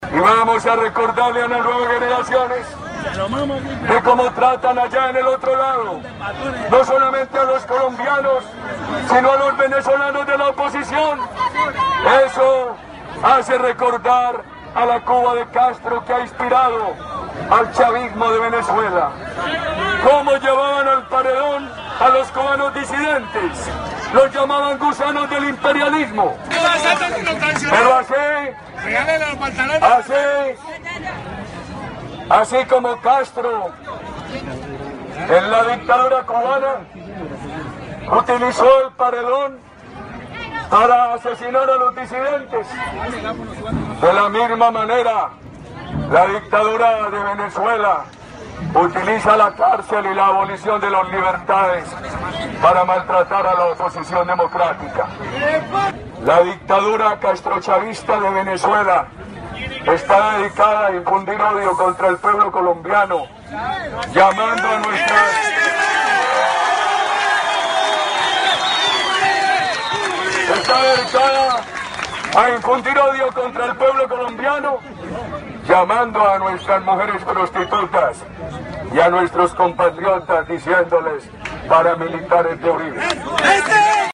Alocución de Álvaro Uribe.